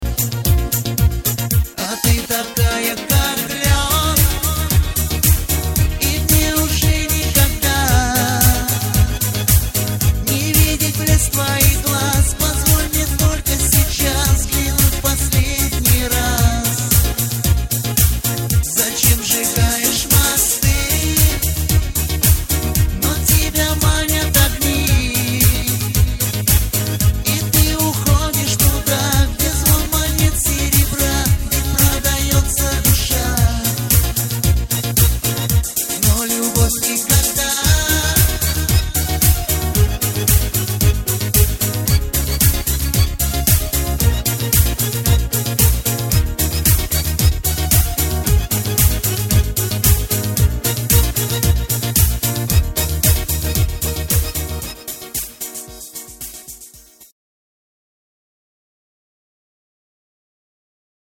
• Качество: 320, Stereo
грустные
русский шансон